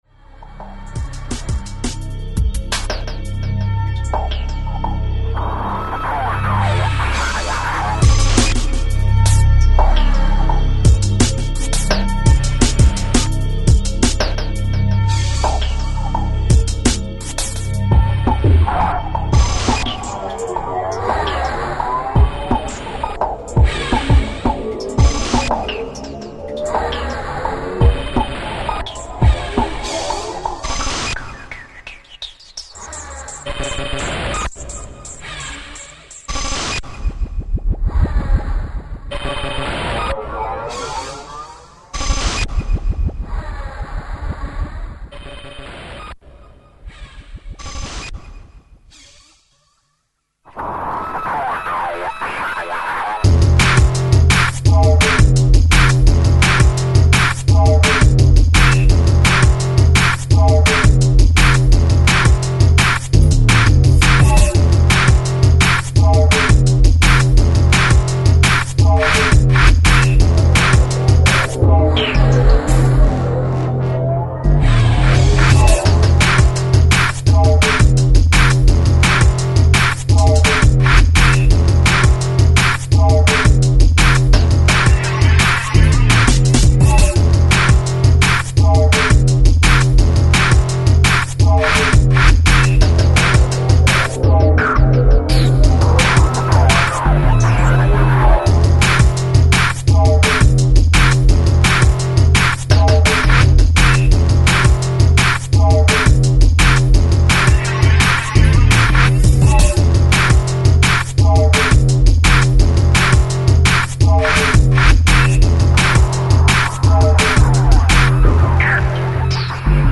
futuristic dark sounds